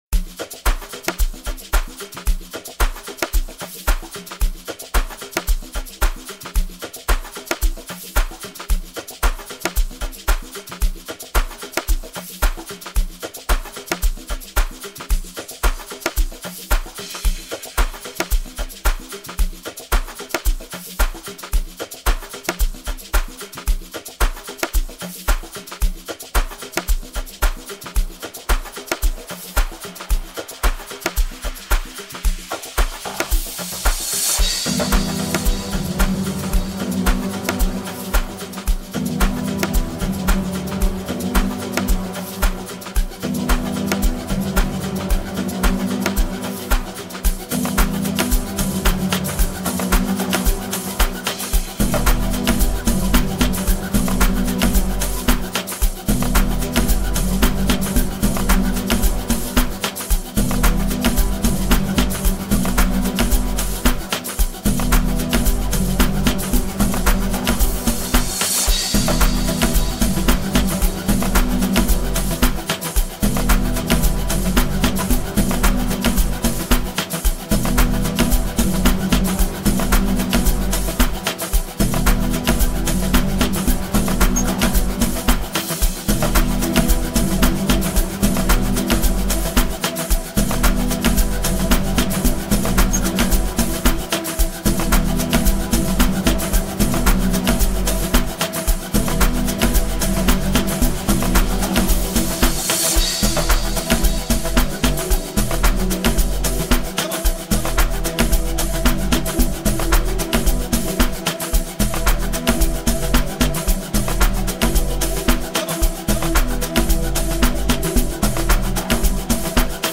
a hot new banger
With his headbanging productions and unrelenting dedication